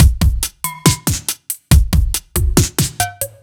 Index of /musicradar/french-house-chillout-samples/140bpm/Beats
FHC_BeatD_140-02.wav